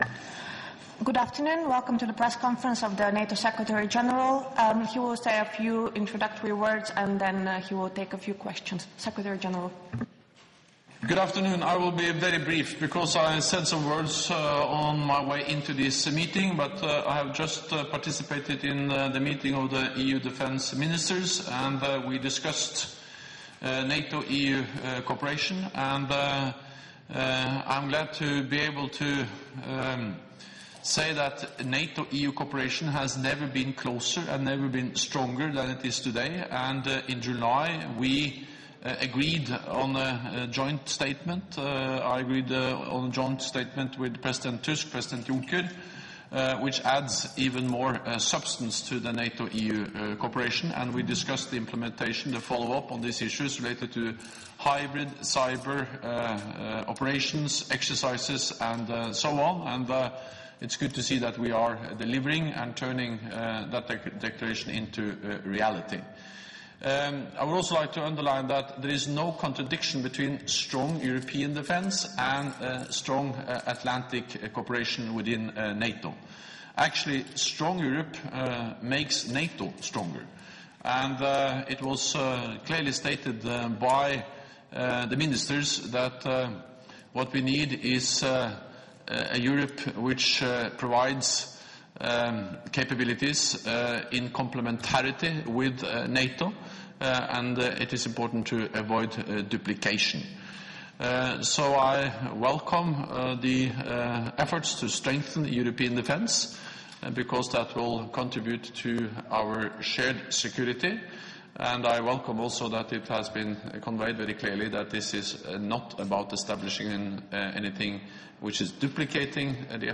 Press point by NATO Secretary General Jens Stoltenberg following informal EU Defence Ministers meeting, Bratislava
(As delivered)